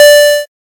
safe-5.ogg.mp3